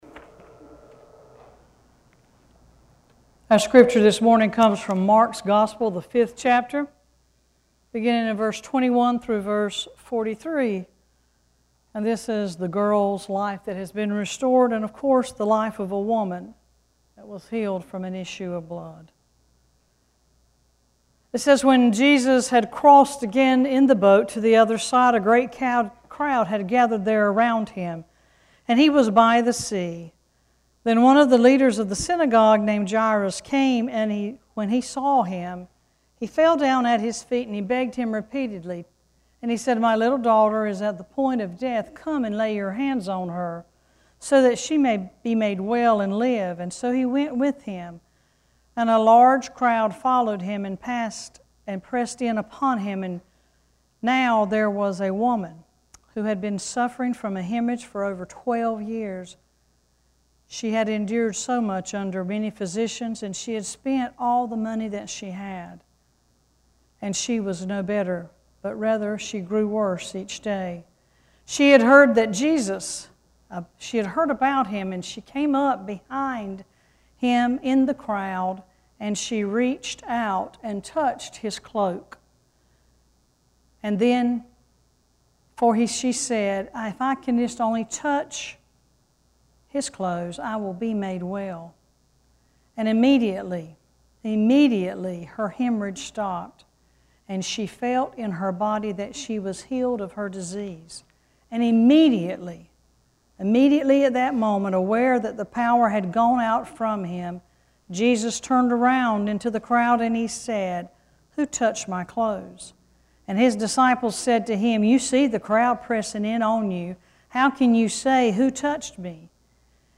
Worship Service 7-01-18: “Getting in Touch”
7-1-18-scripture.mp3